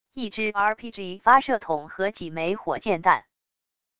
一支rpg发射筒和几枚火箭弹wav音频